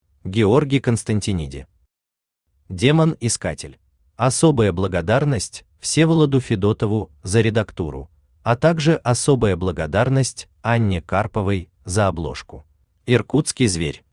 Аудиокнига Демон-искатель | Библиотека аудиокниг
Aудиокнига Демон-искатель Автор Георгий Агафонович Константиниди Читает аудиокнигу Авточтец ЛитРес.